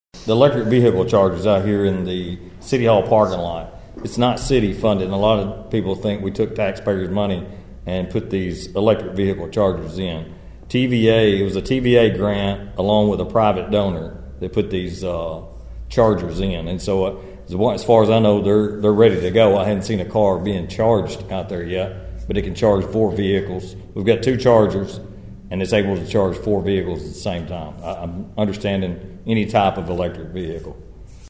During a recent City Council meeting, Johnny McTurner addressed the recently installed chargers.(AUDIO)